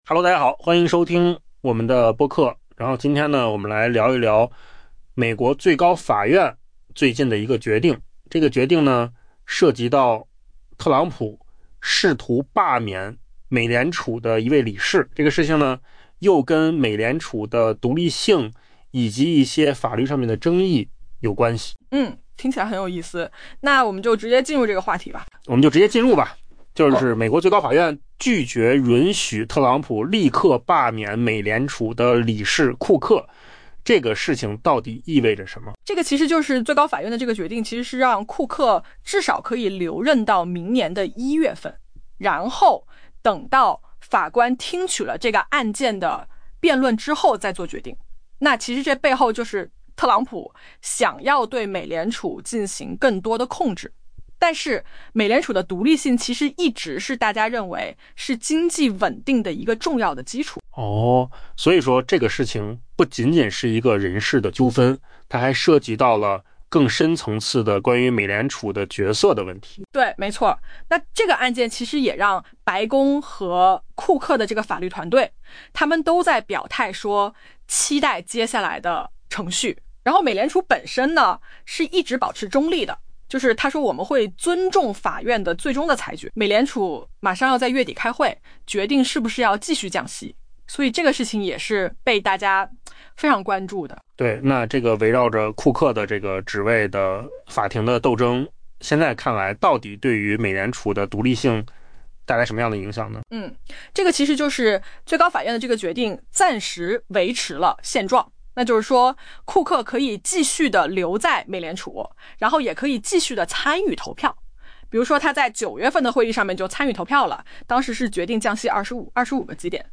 【文章来源：金十数据】AI播客：换个方
AI 播客：换个方式听新闻 下载 mp3 音频由扣子空间生成 美国最高法院拒绝允许特朗普立即罢免美联储理事库克，后者正通过诉讼争取保留职位，这对特朗普试图加强对美联储控制权的努力构成打击。